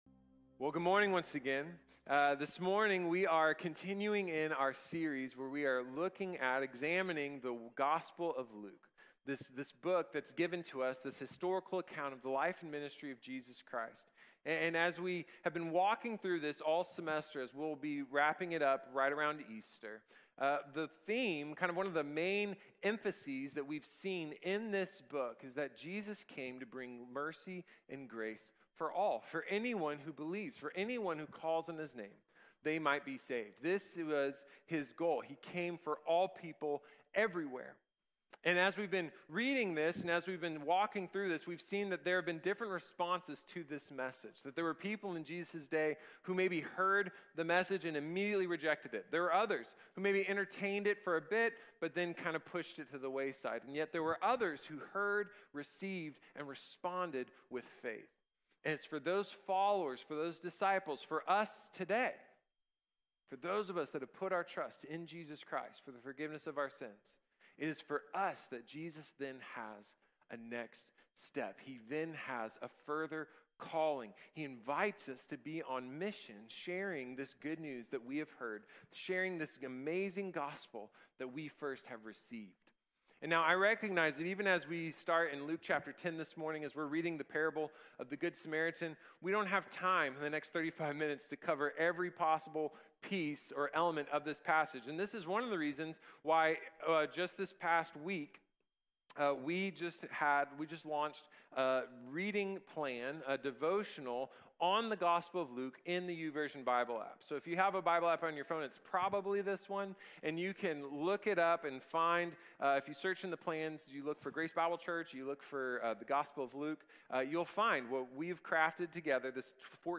The Good Samaritan | Sermon | Grace Bible Church